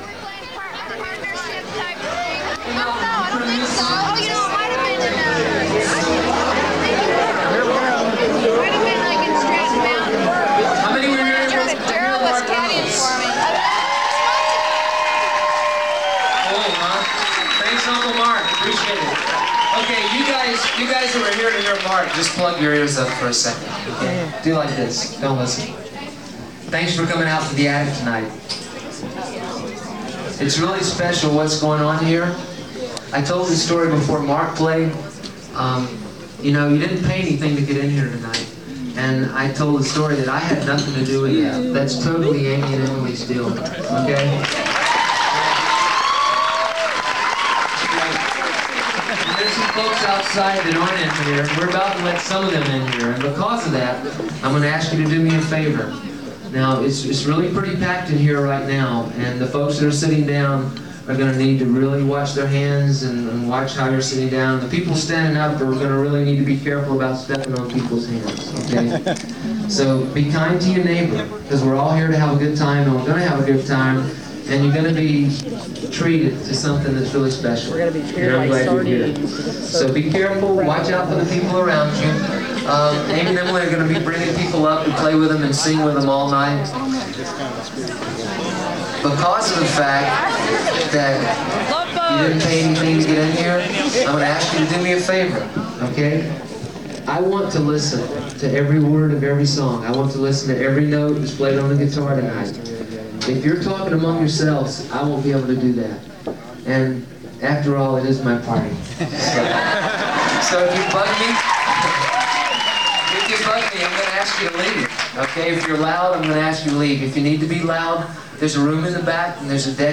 lifeblood: bootlegs: 1993-02-10: eddie's attic - decatur, georgia
(acoustic duo show)